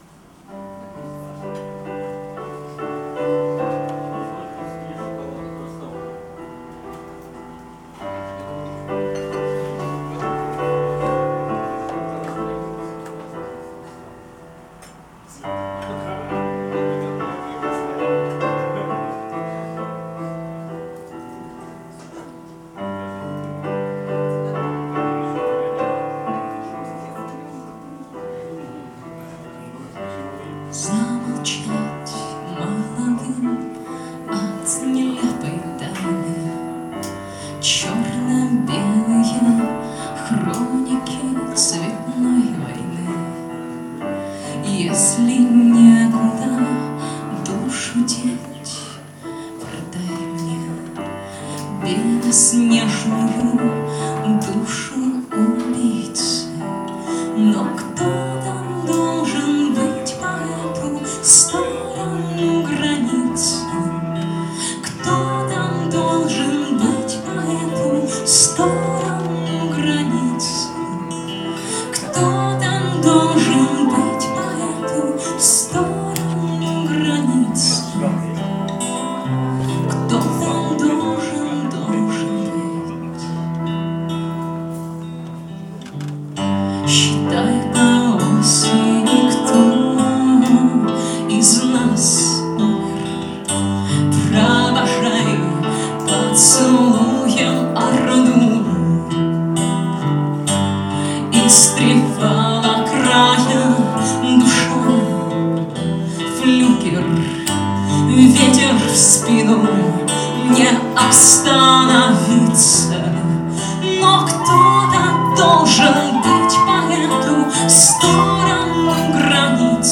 Концерт в КД 21.09.12